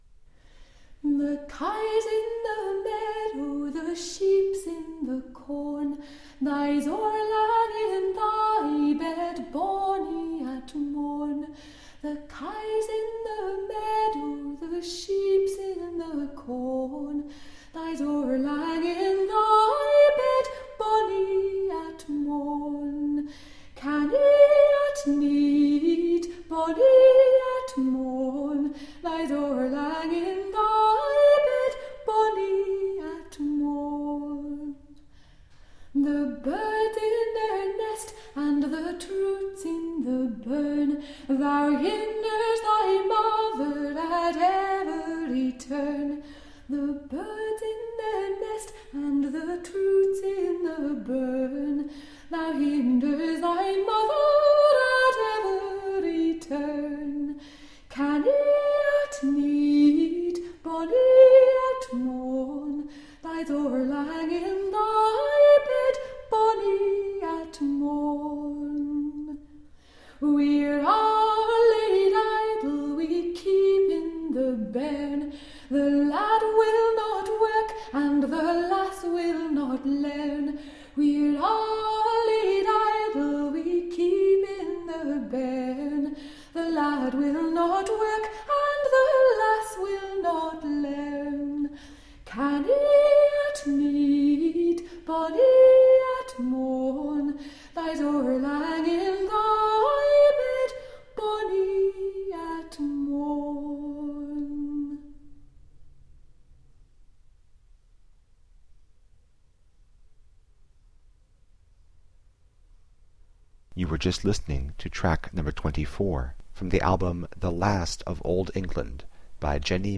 Elizabethan lute and folk song.
Classical, Renaissance, Classical Singing
Lute